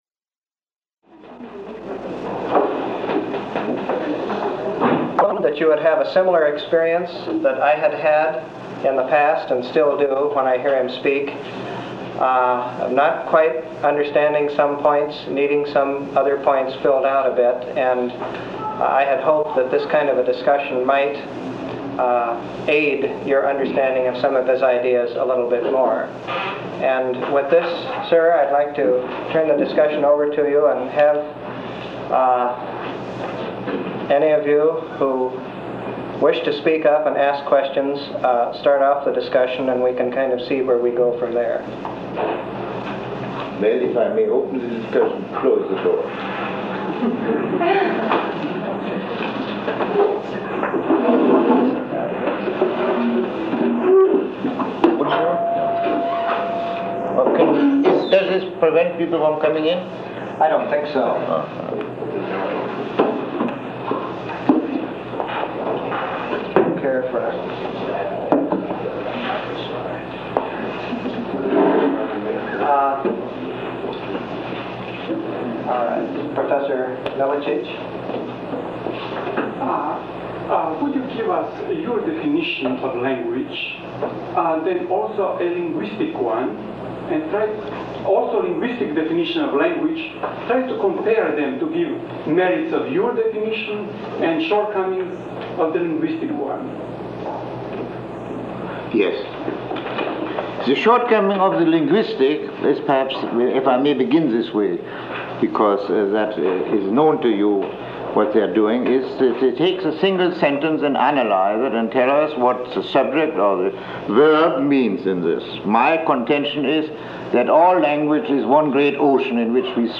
Lecture 03